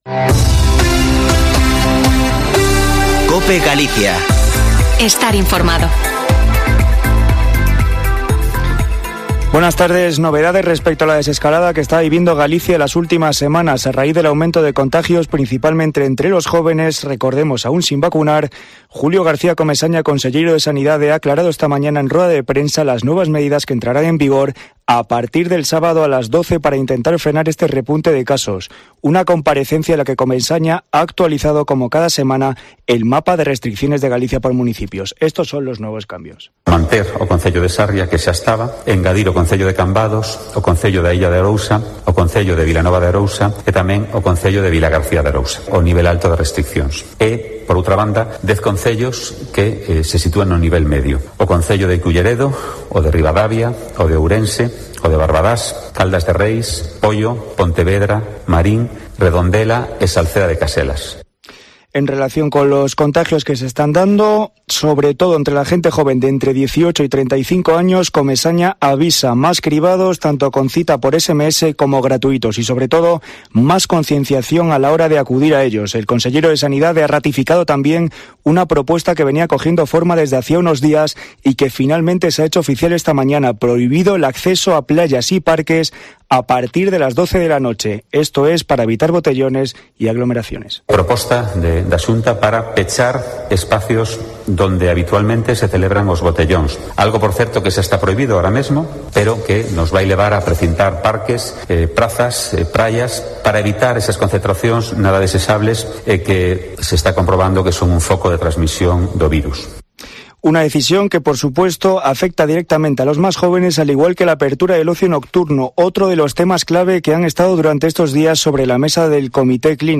Informativo Mediodía en Cope Galicia 07/07/2021. De 14.48 a 14.58h